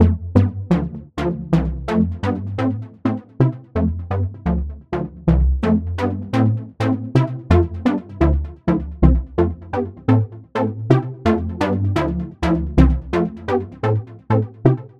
128 Bpm深宫和弦
描述：使用Ableton Live 8创建的Deep House合成器循环。
Tag: 合成器 循环 众议院